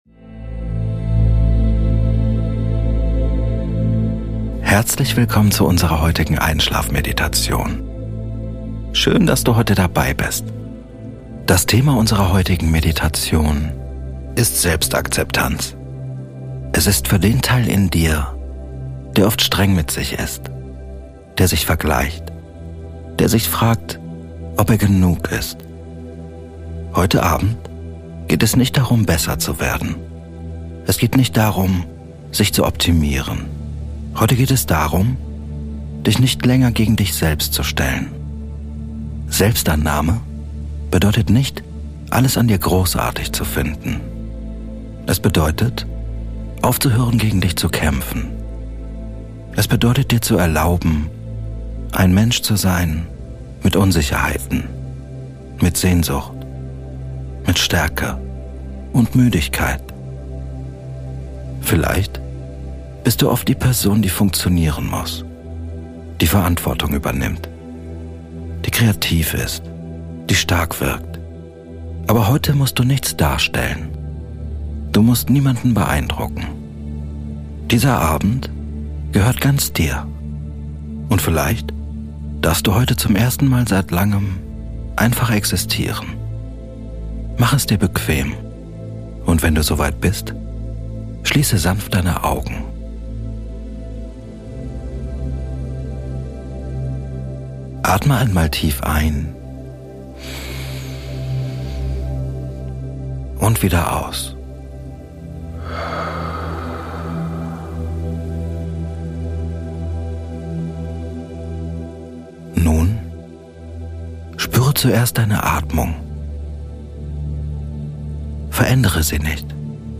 Die Meditation kombiniert Achtsamkeit, Selbstliebe, Stressabbau und mentale Entspannung in einer ruhigen, persönlichen Begleitung durch die Abendstunden. Schritt für Schritt wirst du vom bewussten Ankommen im Körper über liebevollen inneren Zuspruch bis hin zur Traumphase geführt.
Durch beruhigende Worte, langsame Atemimpulse und stärkenden Zuspruch aktiviert diese Abendmeditation dein parasympathisches Nervensystem und unterstützt dich dabei, schneller einzuschlafen und tiefer zu schlafen.